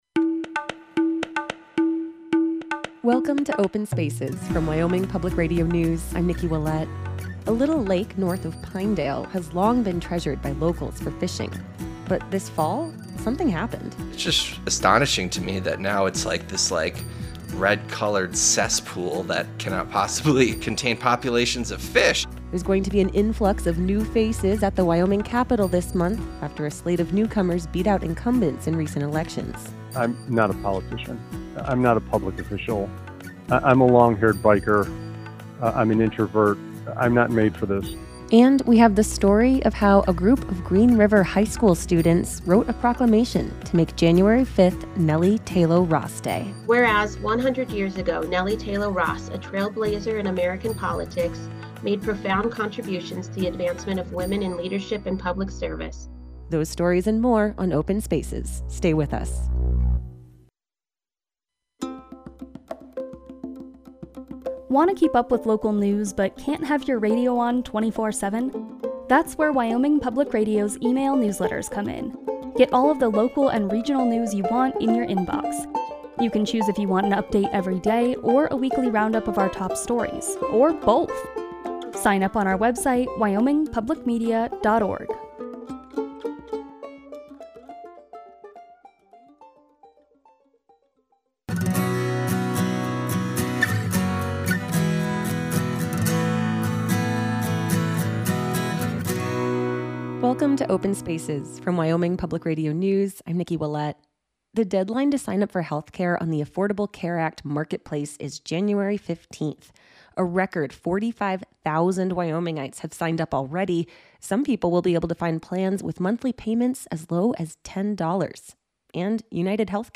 A news and public affairs program about Wyoming and the Mountain West.